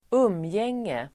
Uttal: [²'um:jeng:e]